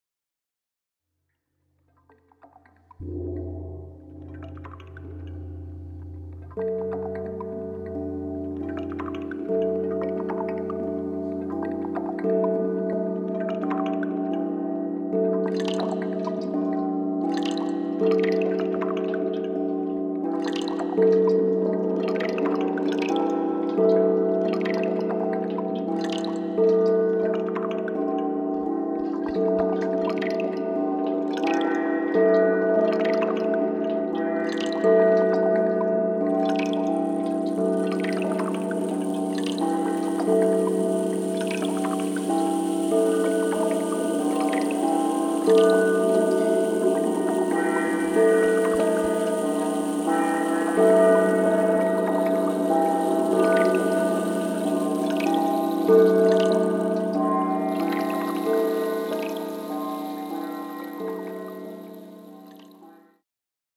mit Didgeridoo, Gongs, Klangschalen und Monochord